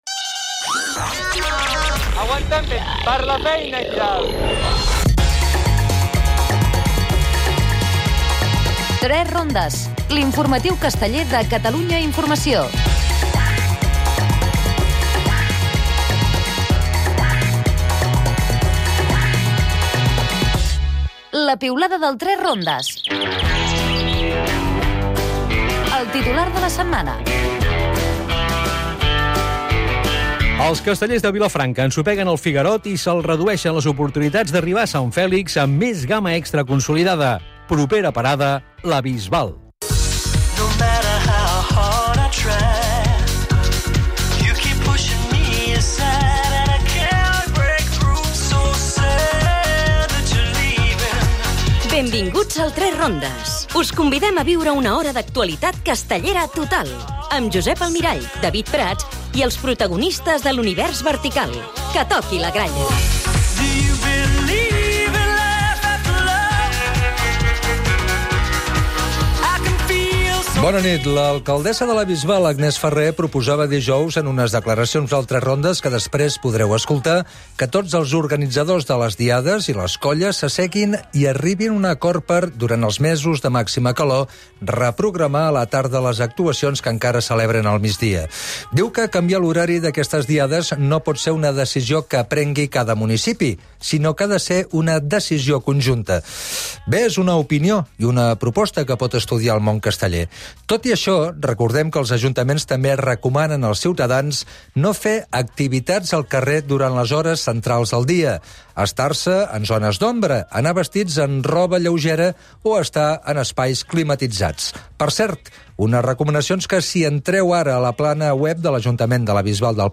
Tertlia